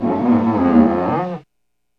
Index of /90_sSampleCDs/E-MU Producer Series Vol. 3 – Hollywood Sound Effects/Water/Rubber Squeegees
SQUEEGEE 5.wav